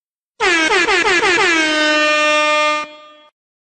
airhorn
Tags: drops